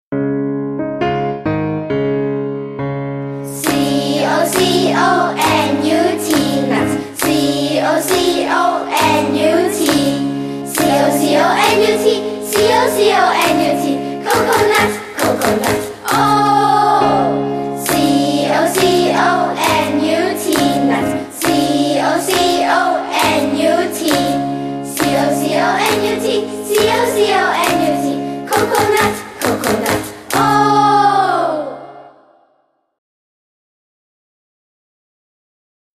Campfire Songs